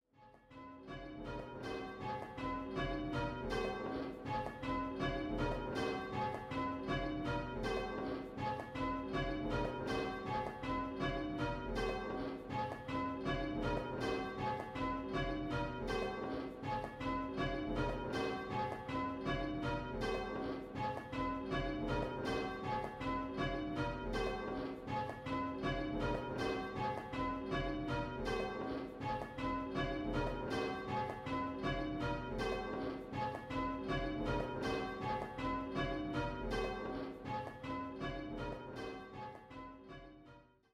5 & 6 Bell Game